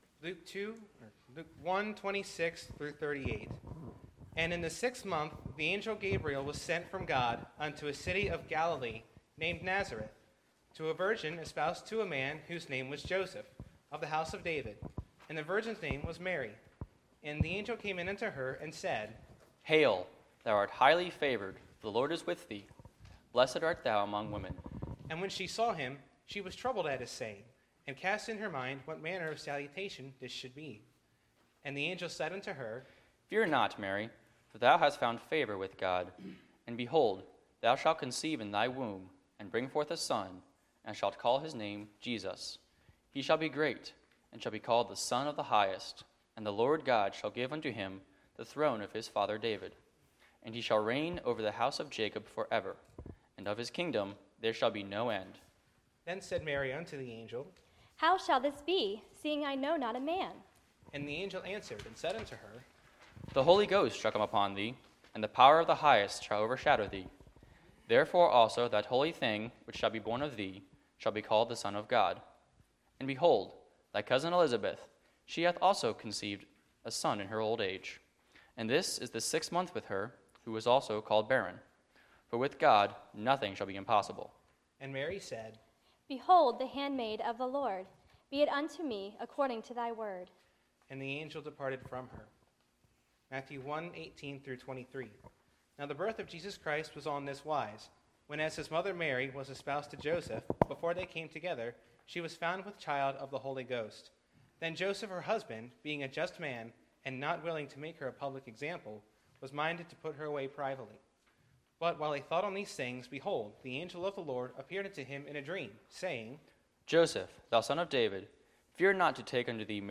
Sunday, December 16, 2017 – Adult Christmas Music Program